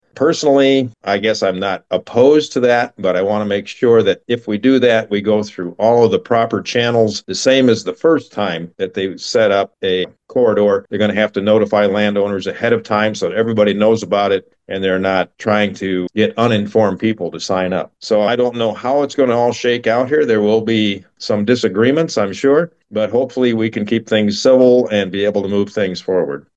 Guth says he’s open to expanding the corridor if it’s done transparently and with proper notice to landowners.